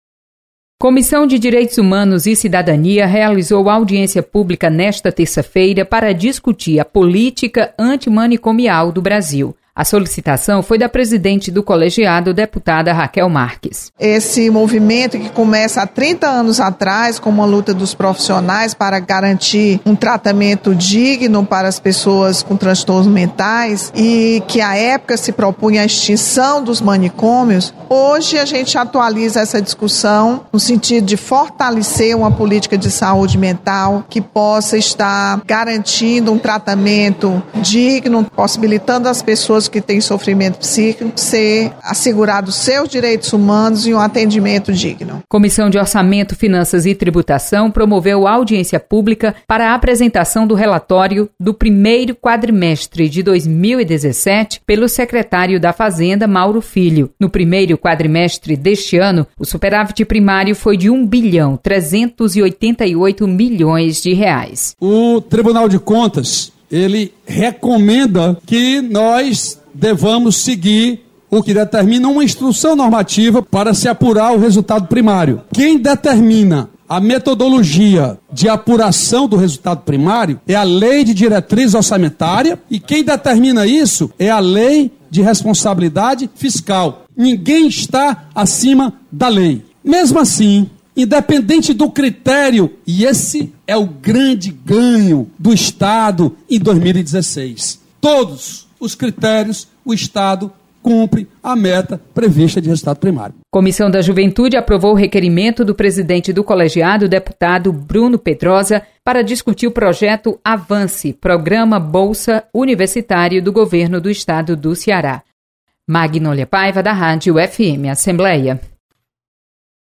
Acompanhe resumo das comissões técnicas permanentes da Assembleia Legislativa. Repórter